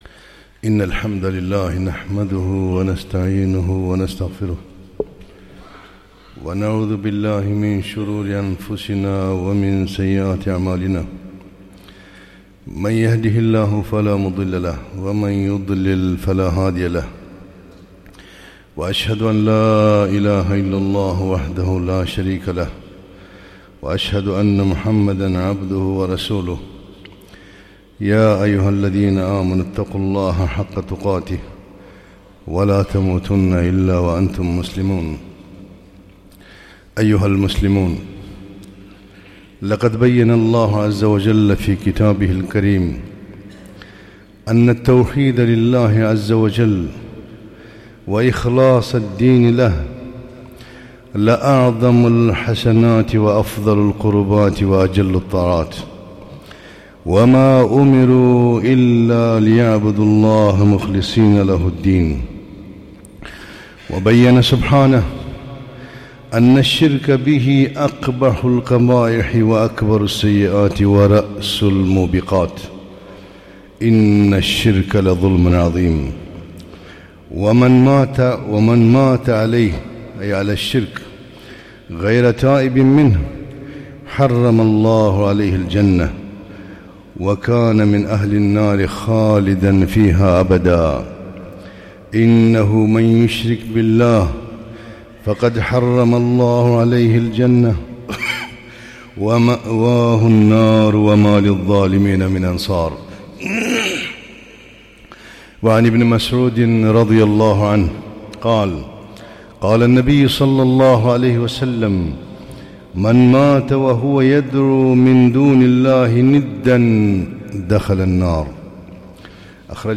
خطبة - سورة الإخلاص صفة الرحمن